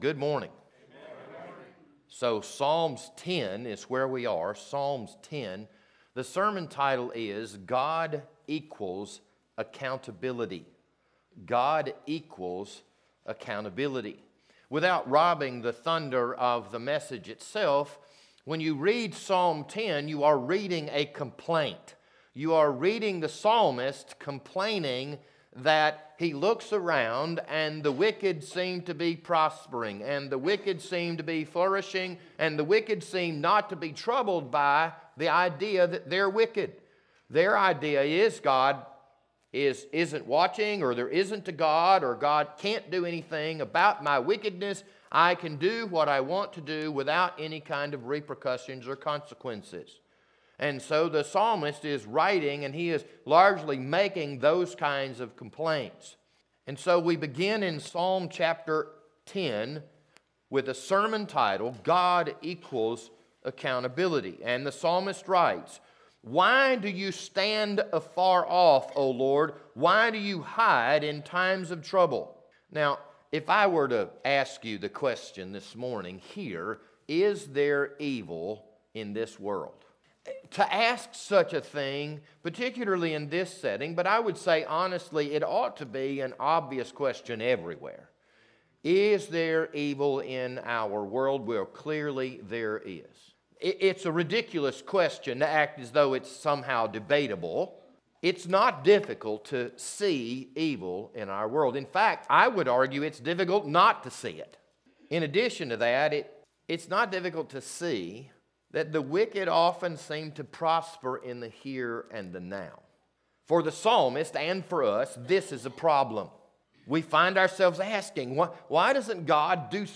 This Sunday morning sermon was recorded on February 20th, 2022.